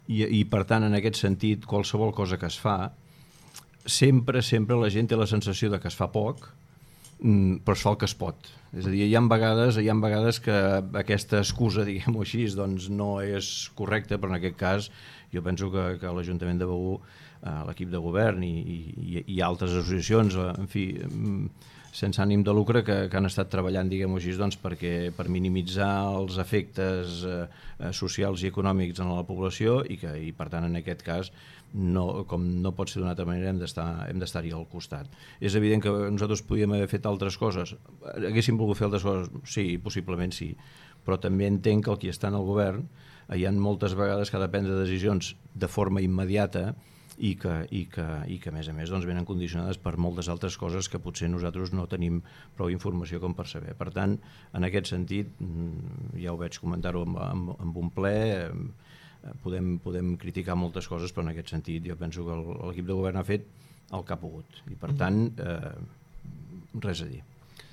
El cap de l’oposició i l’alcalde del municipi debaten les diferents polítiques municipals